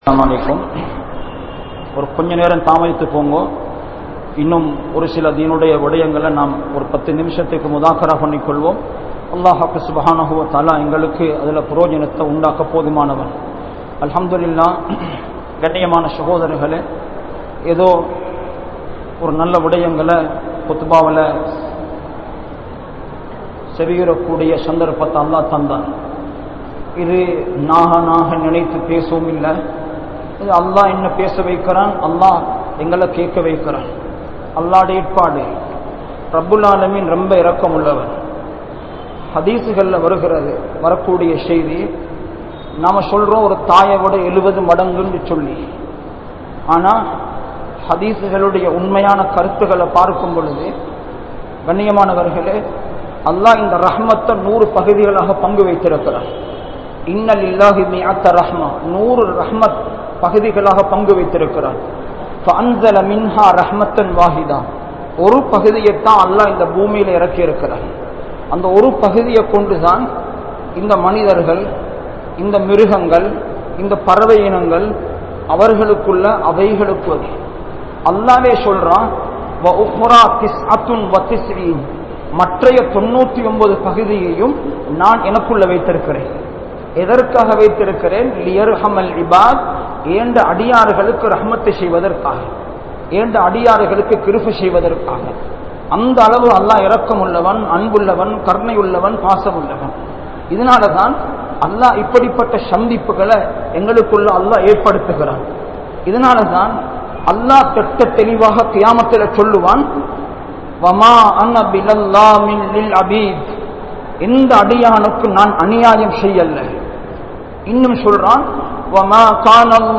Grand Jumua Masjith